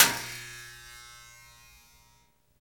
Index of /90_sSampleCDs/Roland L-CD701/PRC_FX Perc 1/PRC_Long Perc
PRC RATTL03R.wav